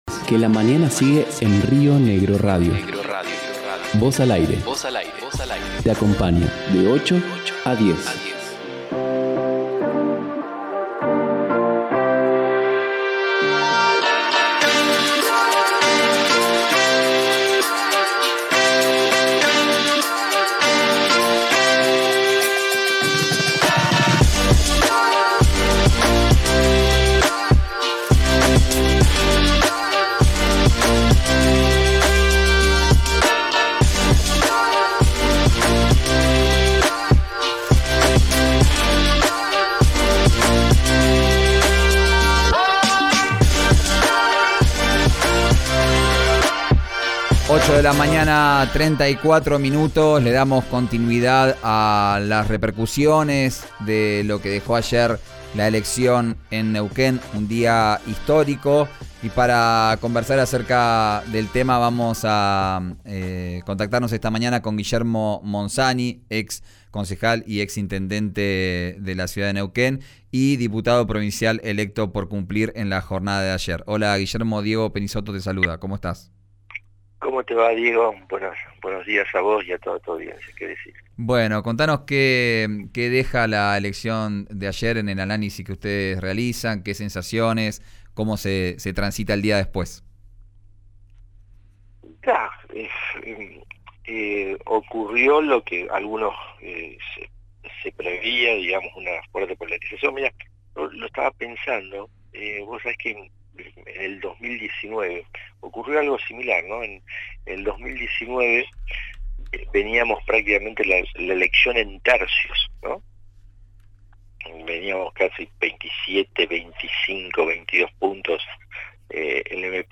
El concejal y diputado provincial electo por el partido Cumplir, Guillermo Monzani, habló en RIO NEGRO RADIO acerca de los resultados, manifestó haber tenido más expectativas pero aseguró un crecimiento en el país por parte del sector liberal.